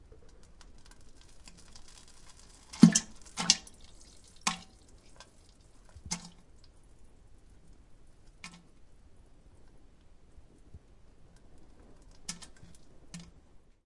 拉大便
描述：在厕所里。
标签： 拉屎 pooping excrementing 妈的 妈的 恶心 粪便 粪便 厕所
声道立体声